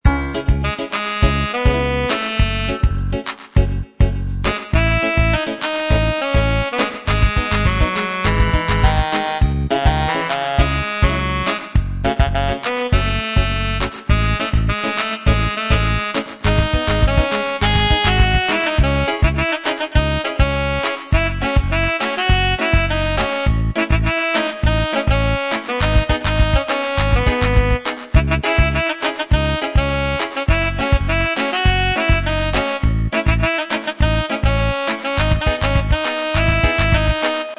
• Category Pop